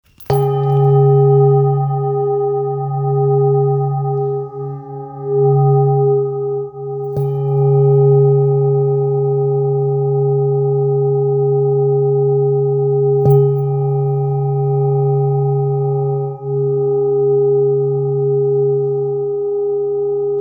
Singing Bowl, Buddhist Hand Beaten, with Fine Etching Carving, Mandala, Sri Yantra, Thangka Color Painted
Material Seven Bronze Metal
When played, the bowls produce a rich, harmonious sound that is said to stimulate the chakras and bring balance to the body's energy centers.